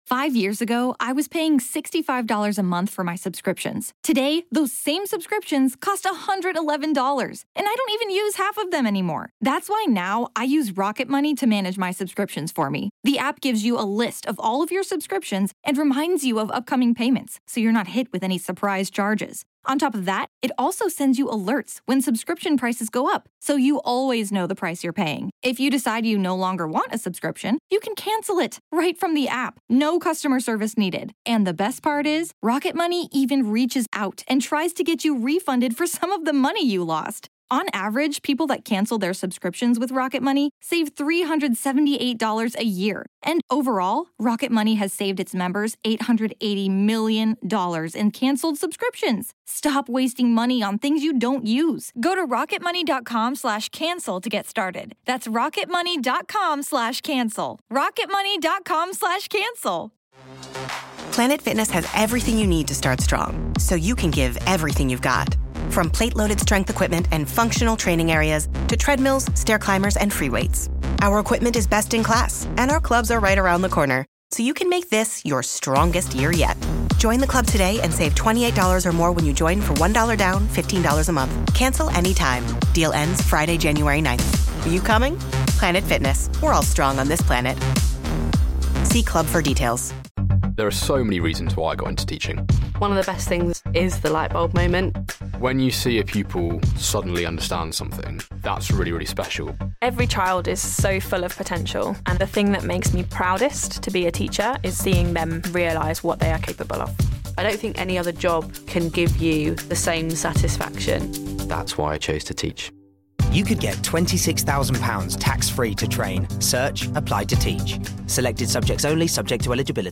E3 2016: Gears Of War 4 Interview